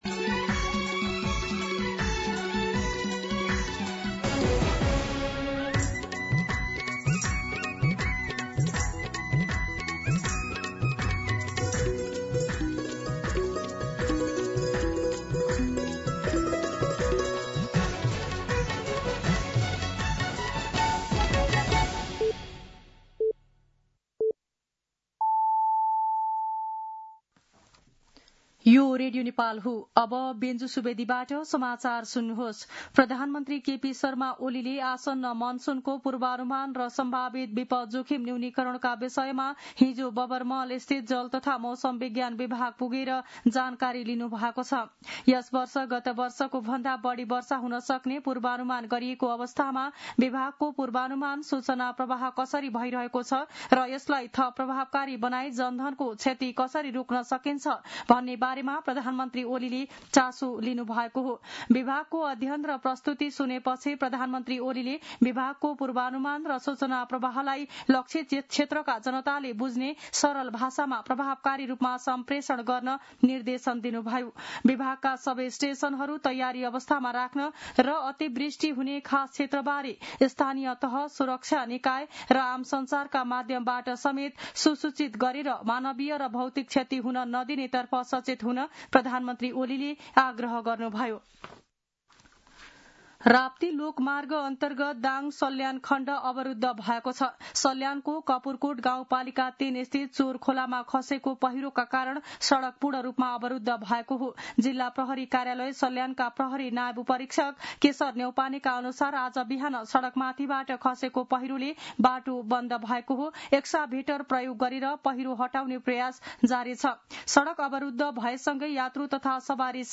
मध्यान्ह १२ बजेको नेपाली समाचार : १४ जेठ , २०८२